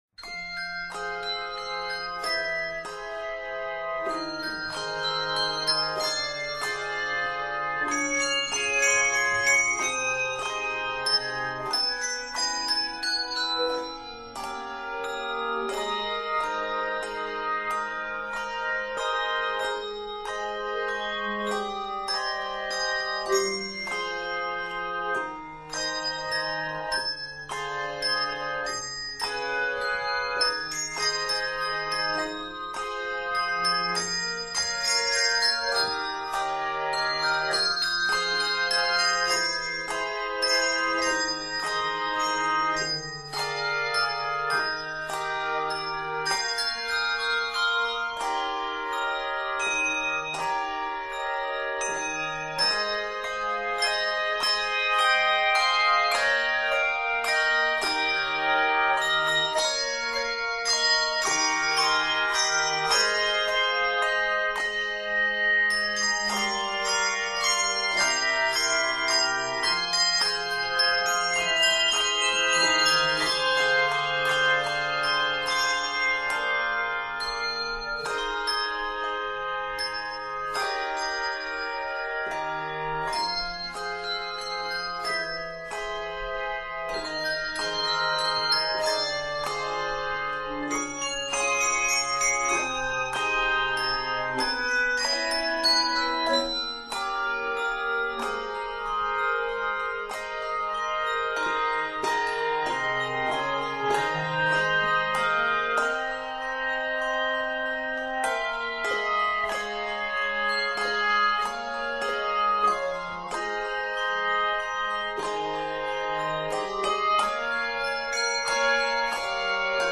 handbells. This piece demands good phrasing and dynamics.